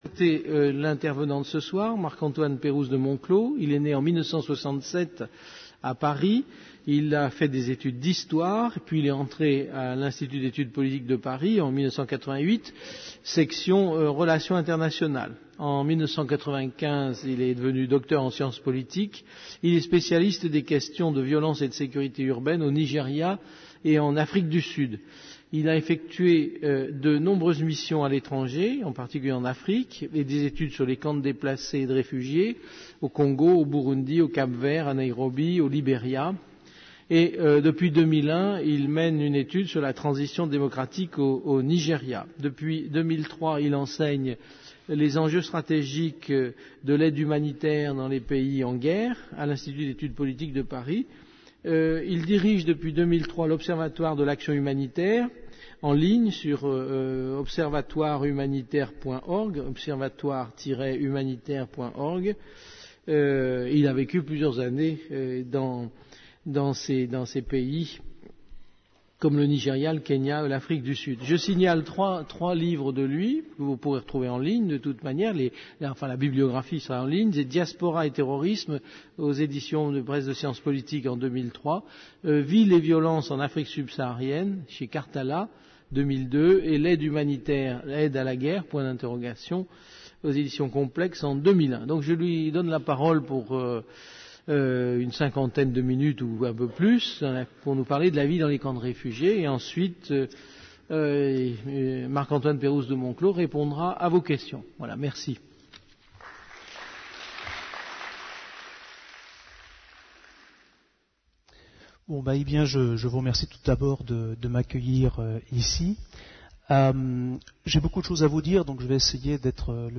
Un conférence de l'Université de tous les savoirs